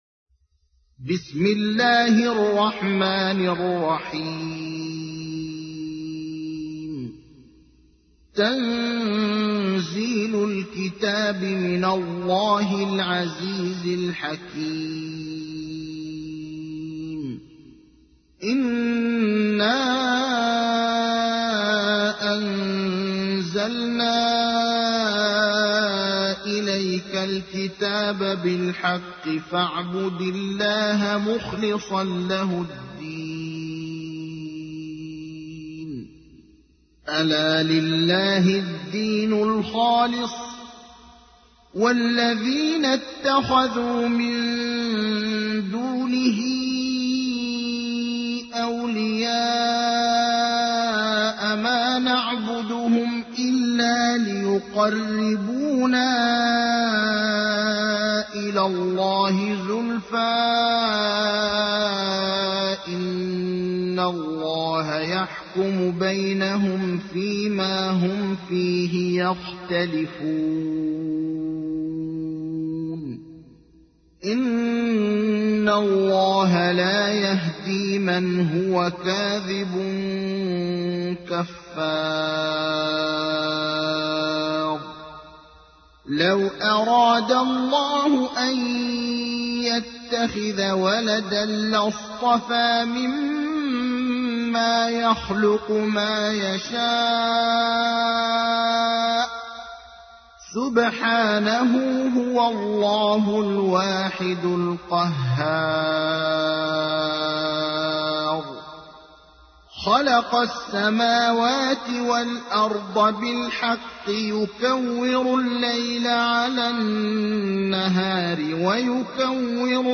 تحميل : 39. سورة الزمر / القارئ ابراهيم الأخضر / القرآن الكريم / موقع يا حسين